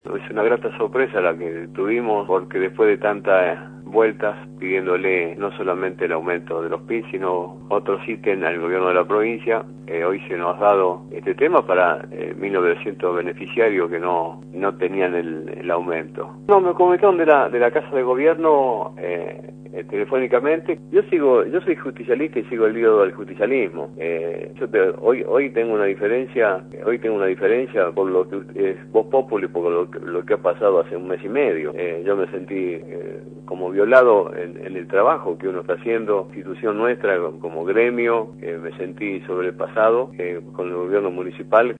Los gastos para el 20 de mayo “son necesarios para La Rioja”, dijo Bosetti por Radio La Redparque de la ciudad (3)